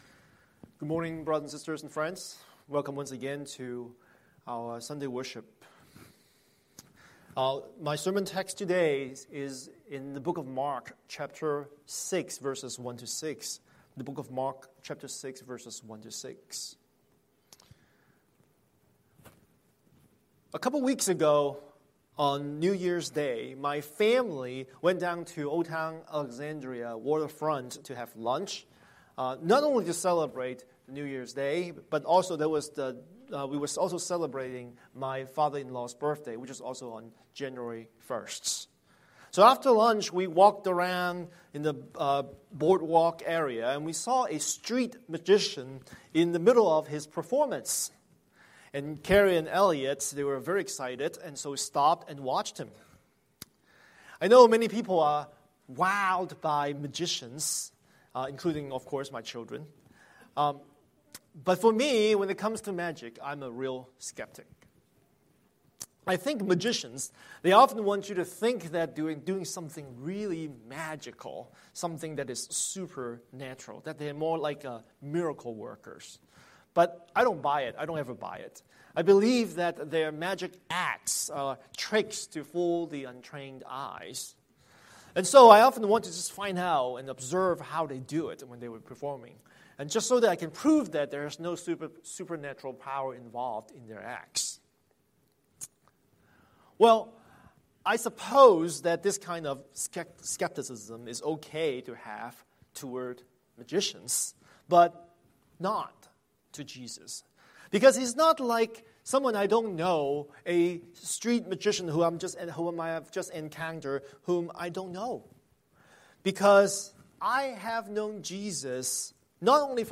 Scripture: Mark 6:1–6 Series: Sunday Sermon